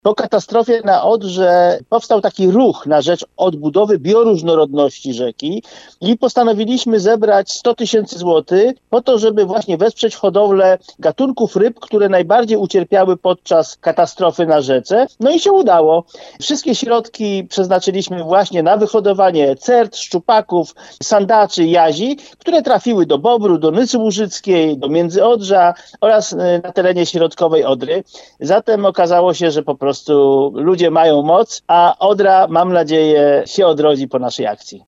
– dla Twojego radia mówił ekolog